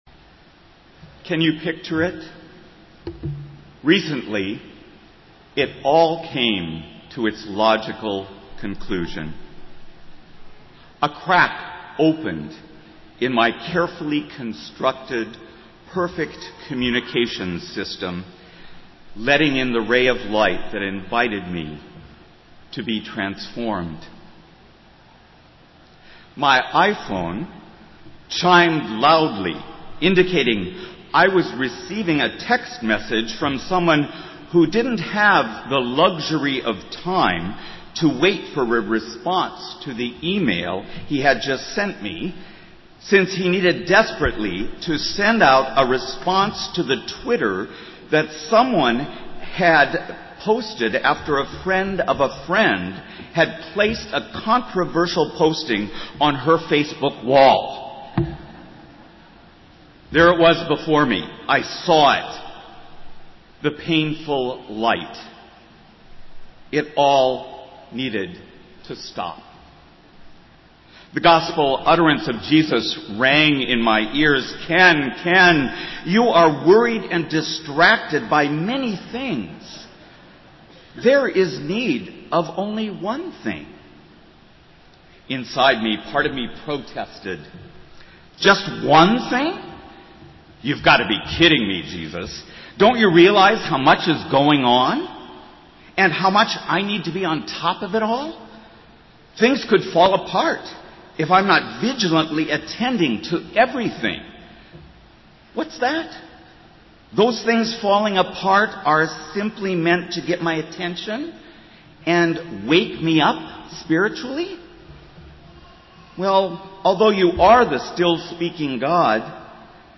Festival Worship - Eighth Sunday after Pentecost